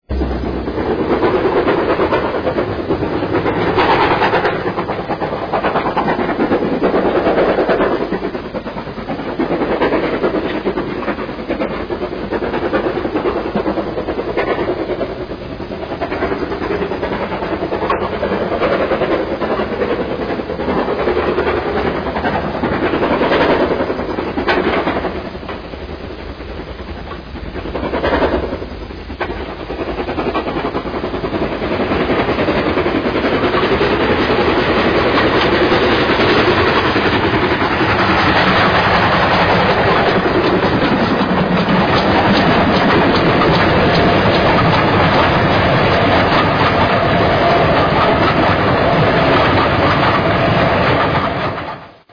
Sounds of Great Western steam locomotives